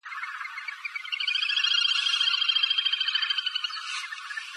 Малая поганка (Tachybaptus ruficollis (Pallas, 1764))
tachybaptus_ruficollis3.mp3